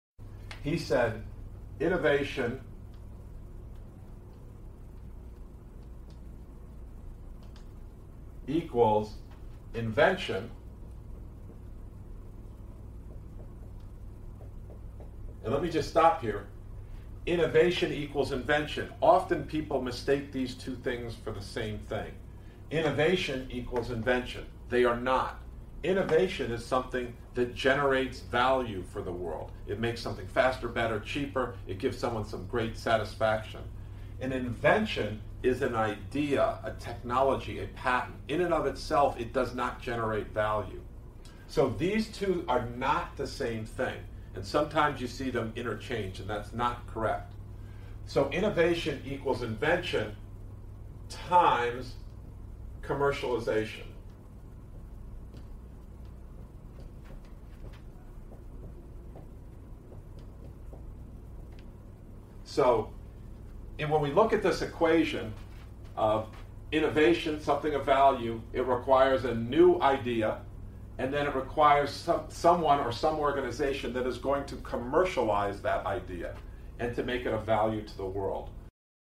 Sample: You will hear an Interview/Lecture.